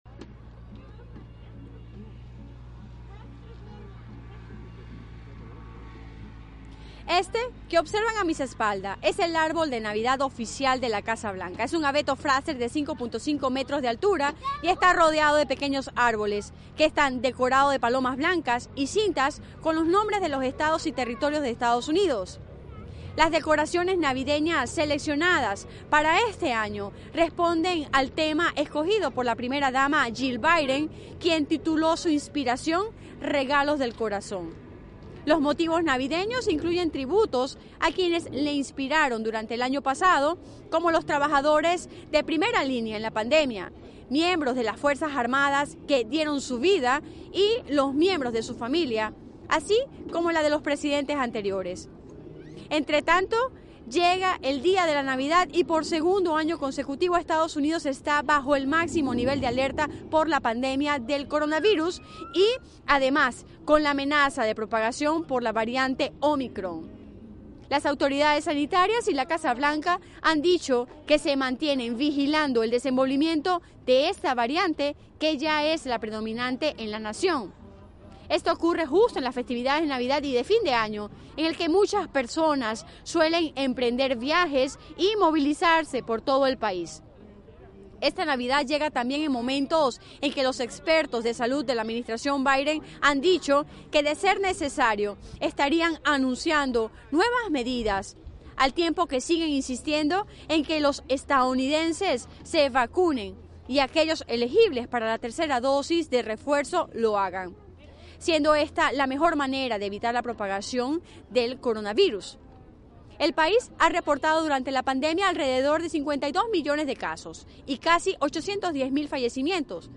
La agenda del día [Radio]